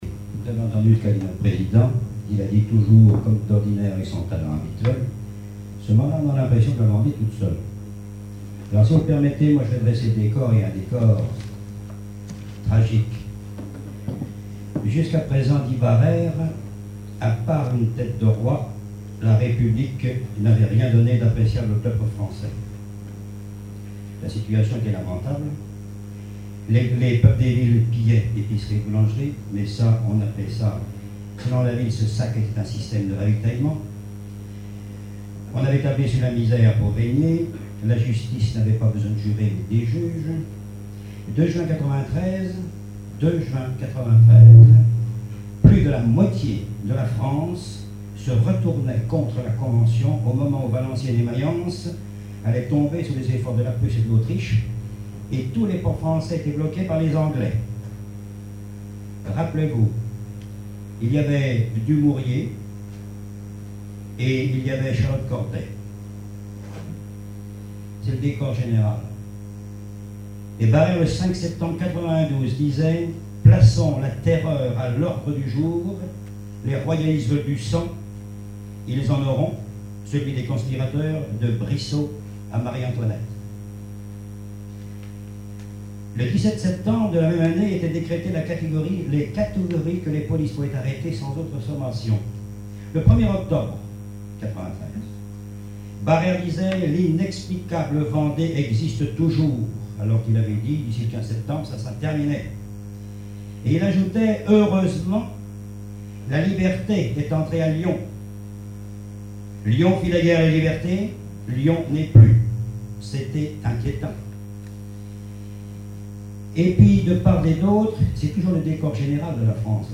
congrès, colloque, séminaire, conférence
Conférence de la Société des écrivains de Vendée